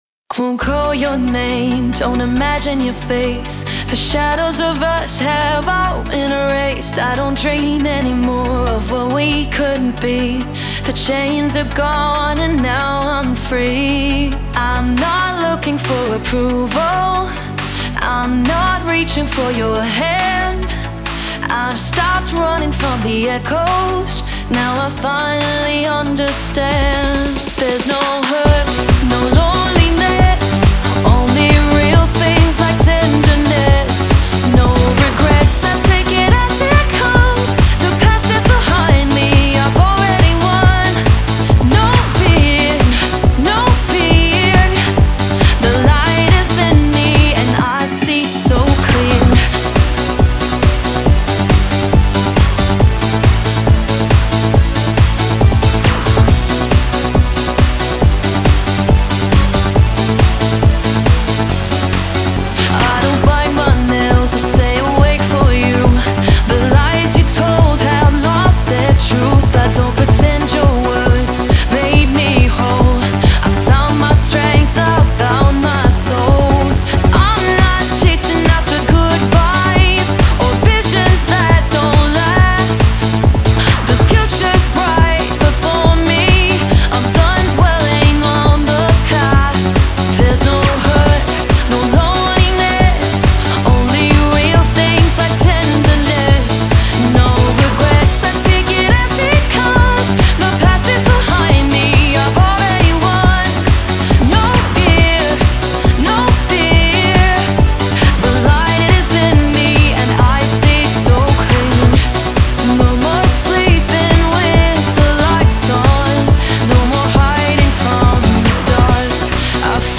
Genre: Eurodance